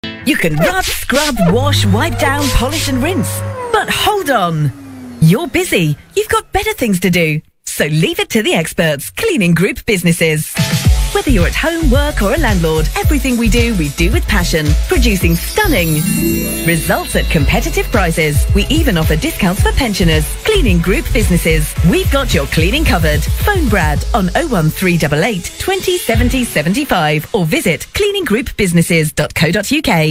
Cleaning-Group-Businesses-Radio-Advert-online-audio-converter.com_.mp3